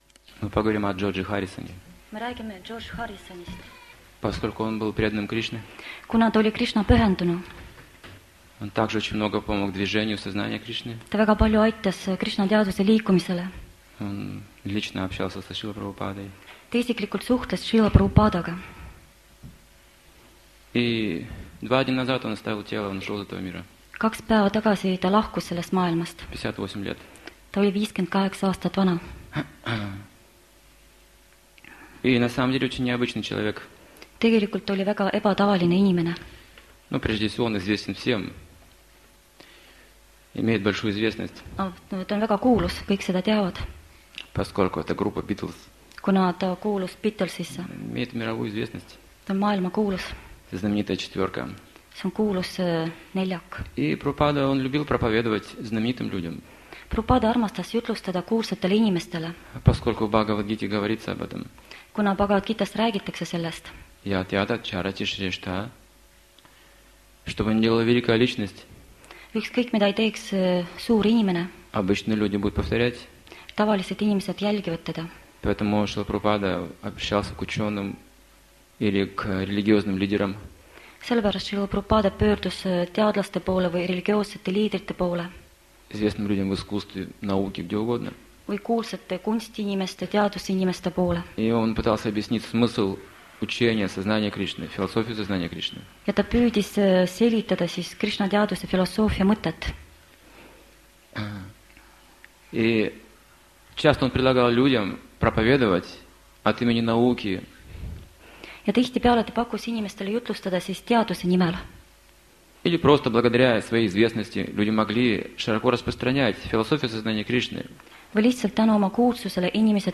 Лекции и книги